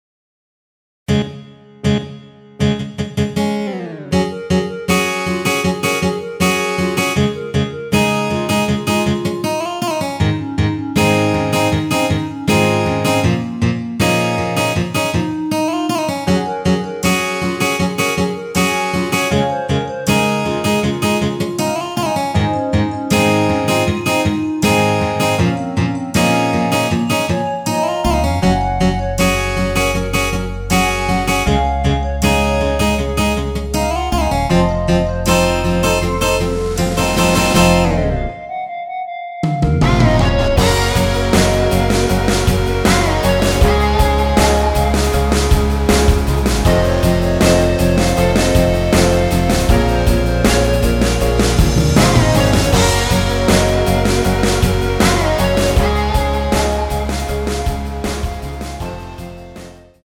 원키에서(-8)내린 멜로디 포함된 대부분의 남성분이 부르실수 있는 키로 제작된 MR입니다.(미리듣기 참조)
Bbm
앞부분30초, 뒷부분30초씩 편집해서 올려 드리고 있습니다.
중간에 음이 끈어지고 다시 나오는 이유는